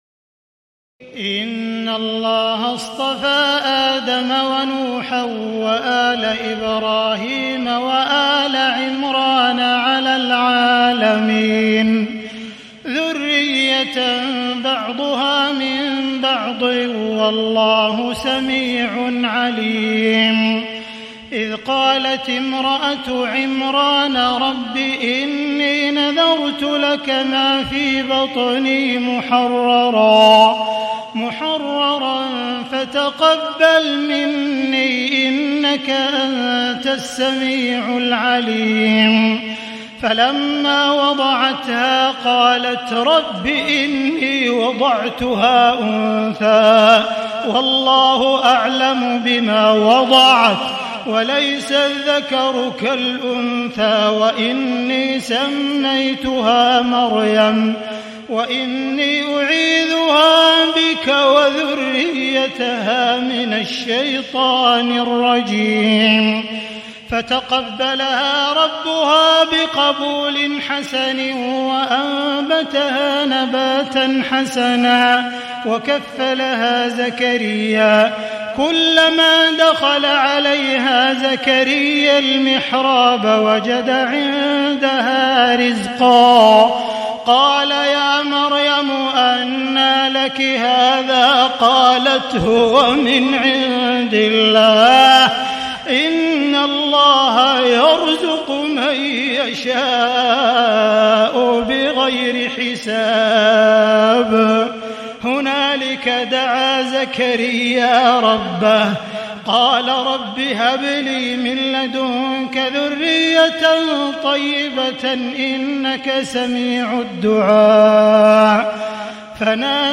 تهجد ليلة 23 رمضان 1437هـ من سورة آل عمران (33-92) Tahajjud 23 st night Ramadan 1437H from Surah Aal-i-Imraan > تراويح الحرم المكي عام 1437 🕋 > التراويح - تلاوات الحرمين